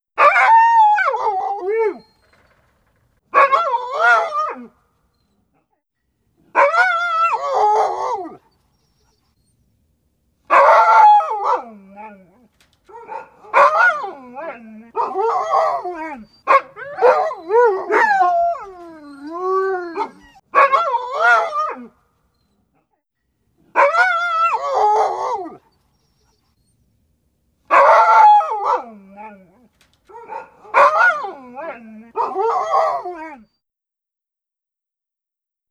animals / dogs